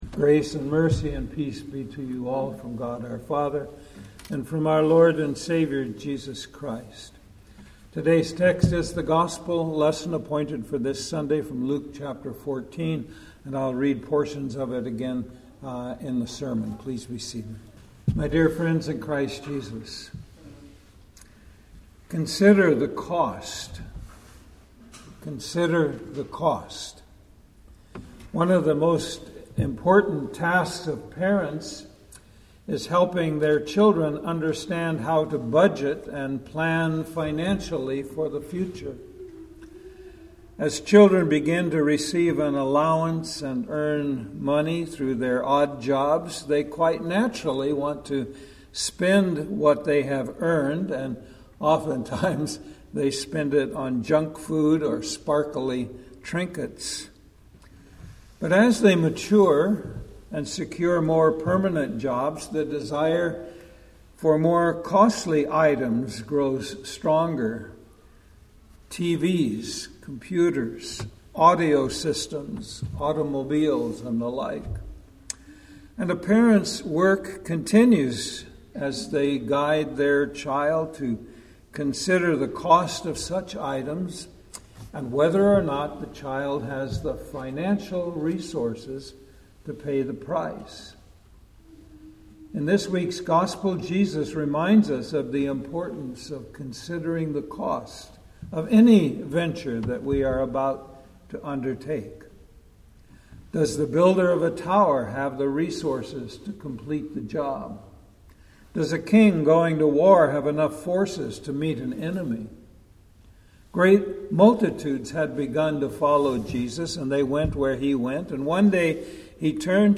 Sermon-September-7-2025.mp3